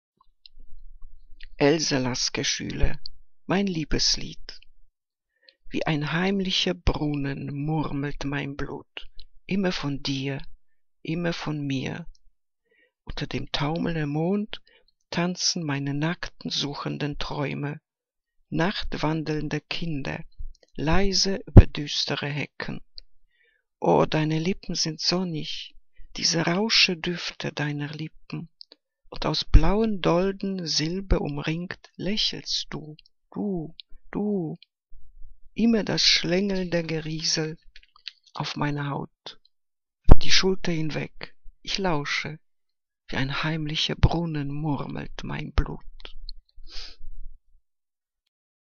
Liebeslyrik deutscher Dichter und Dichterinnen - gesprochen (Else Lasker-Schüler)